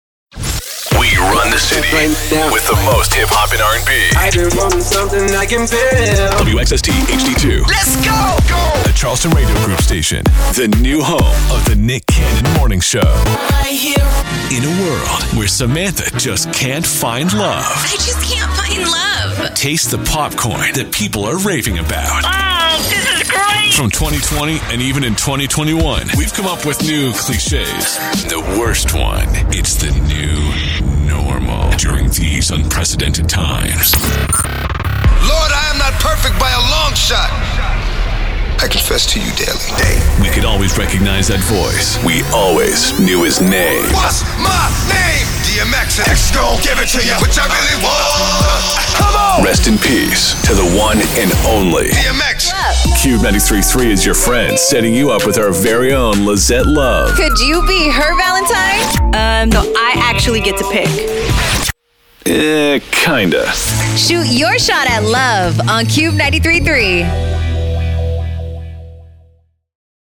VO / Promo